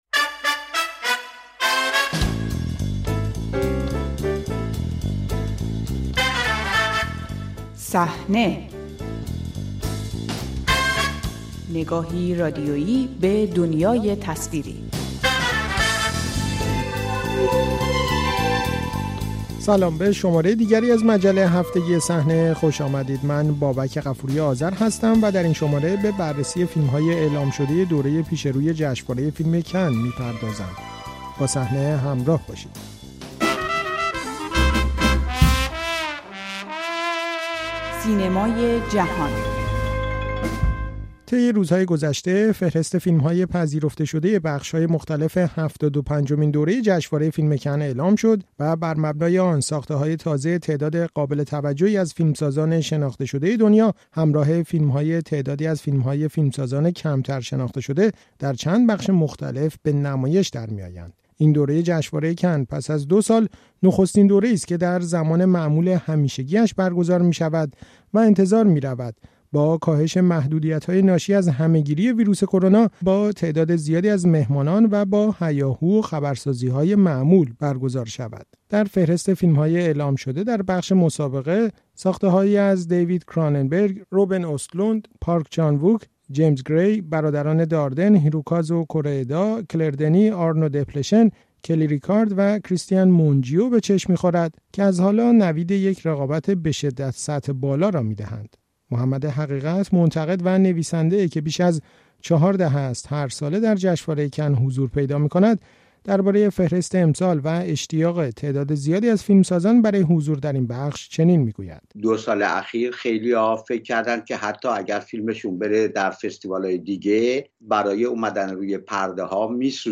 صحنه؛ مروری بر فیلم‌های کن ۲۰۲۲ در گفت‌وگو